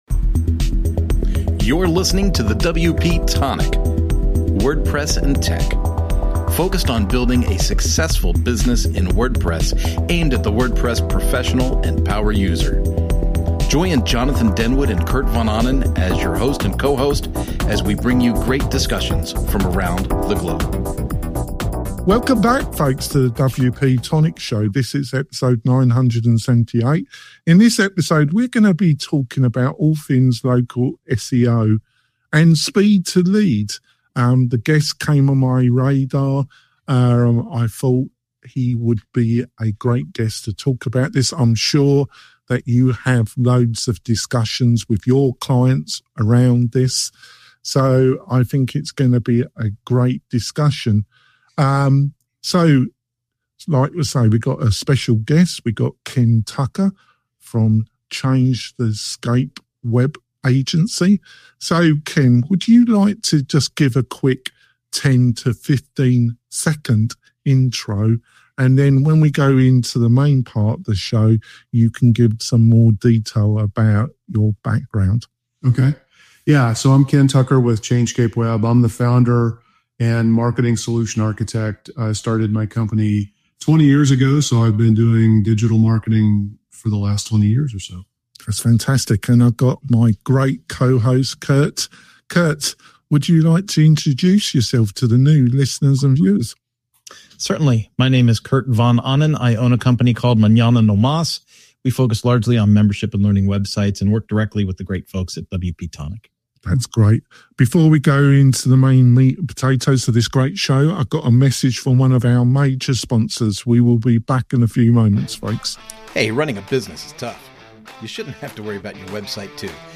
We interview creative WordPress and startup entrepreneurs, plus online experts who share insights to help you build your online business.